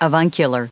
added pronounciation and merriam webster audio
446_avuncular.ogg